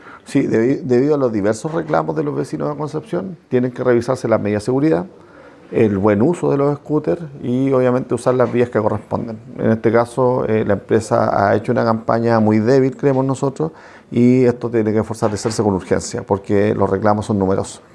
Por lo mismo el municipio de Concepción, está estudiando revocar el permiso que le entregaron a la empresa, debido a que se han registrado problemas, como atropellos de peatones, mal uso de los espacios públicos. El alcalde, Héctor Muñoz así lo explicó.
cuna-scooter-hector-munoz.mp3